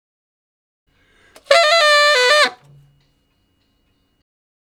066 Ten Sax Straight (D) 36.wav